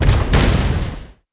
sfx_skill 10_1.wav